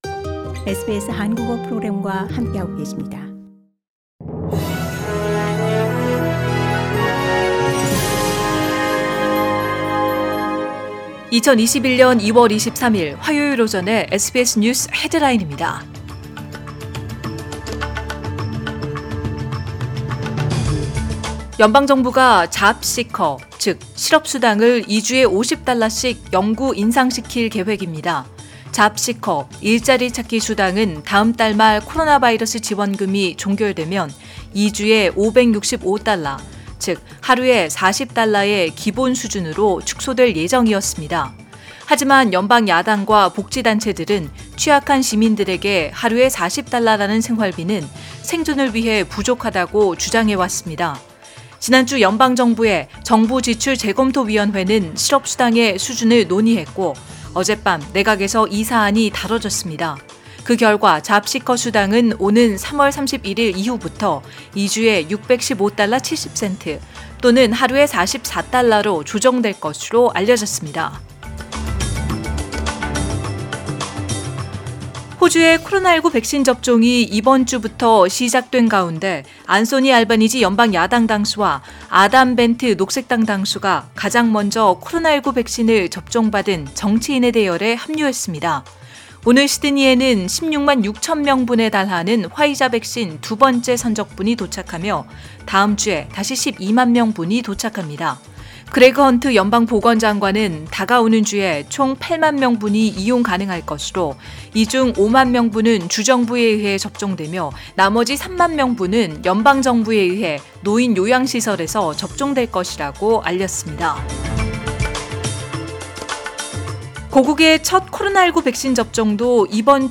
2021년 2월 23일 화요일 오전의 SBS 뉴스 헤드라인입니다.